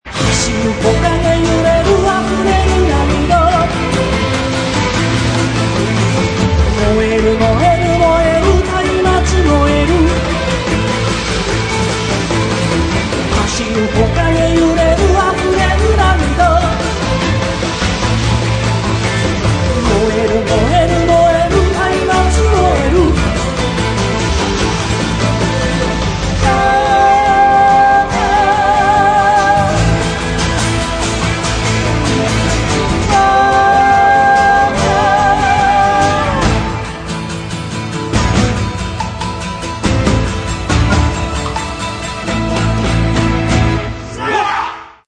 発売当時、通算回数3,500回余りを数えるコンサートの中から、厳選に厳選を重ねた51曲を収録。